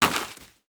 Dirt footsteps 4.wav